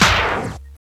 DJ  STOP HIT.wav